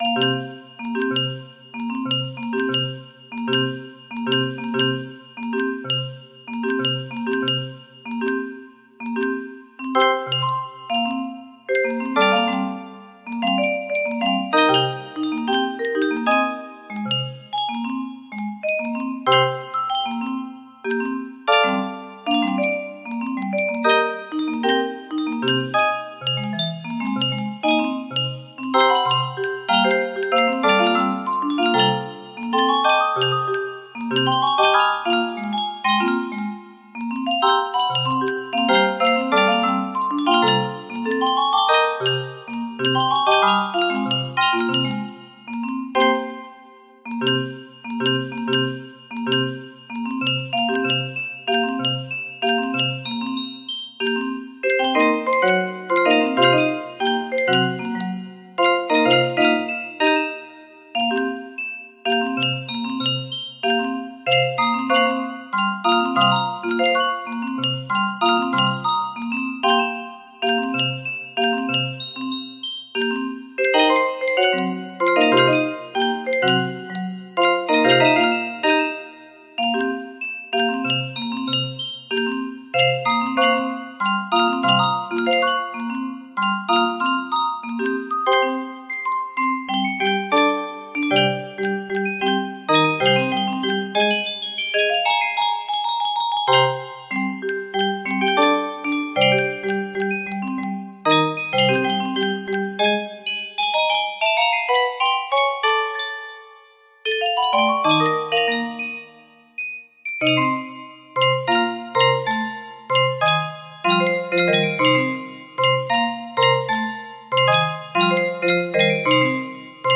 Full range 60
that sounds pretty bad ass for a music box!